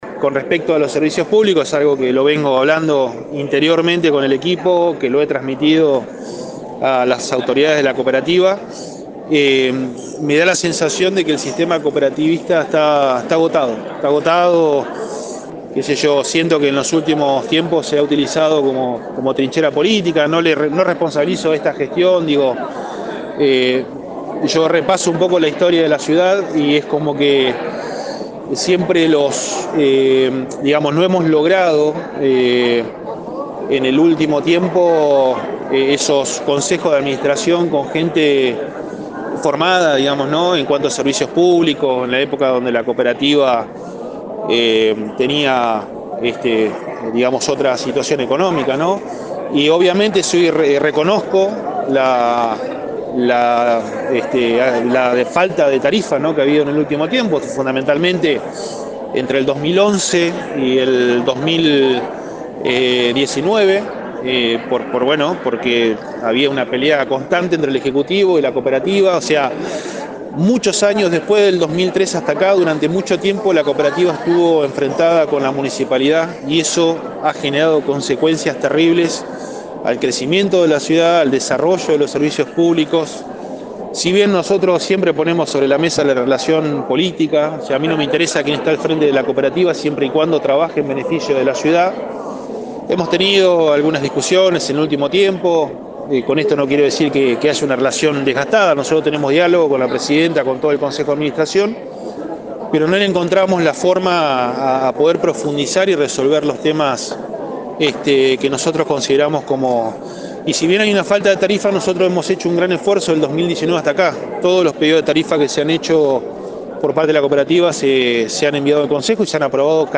Luego del discurso tomo contacto con los medios y amplio el contenido y las razones de su afirmación.